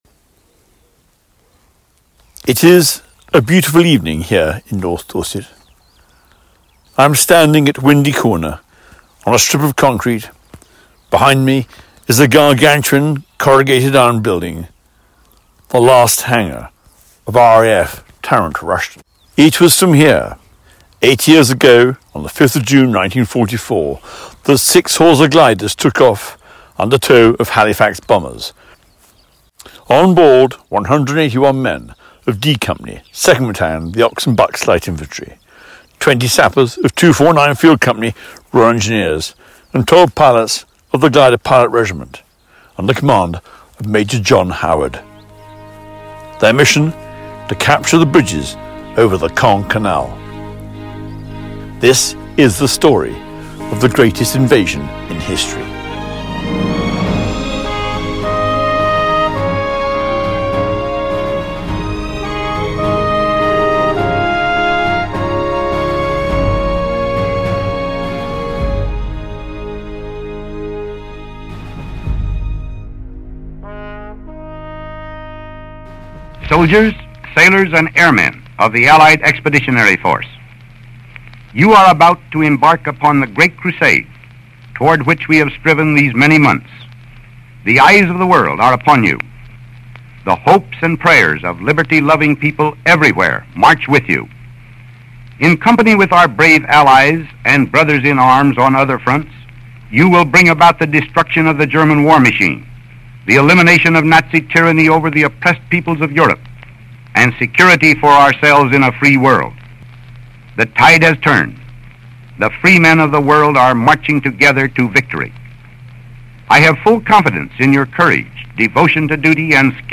Sir Max Hastings joins forces with veterans and military leaders in this special anniversary podcast marking D-Day the 80th anniversary of D-Day.